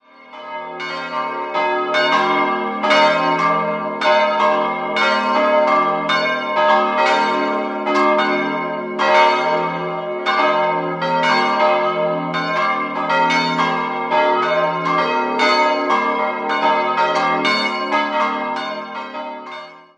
Die auf einer Bergkuppe stehende Philippuskirche wurde in den Jahren 1924 bis 1927 im neoromanischen Stil errichtet. 4-stimmiges Wachet-auf-Geläute: es'-g'-b'-c'' Die vier Eisenhartgussglocken wurden 1926 von der Gießerei Schilling&Lattermann in Apolda gegossen und werden bis heute von Hand geläutet.